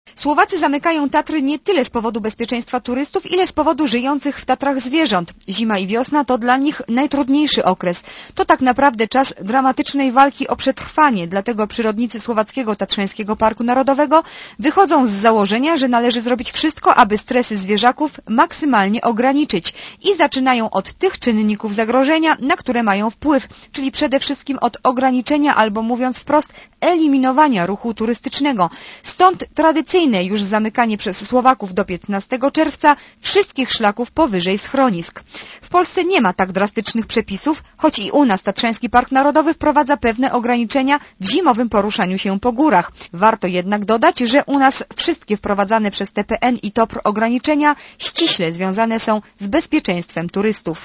Posłuchaj relacji reporterki Radia Zet (198 KB)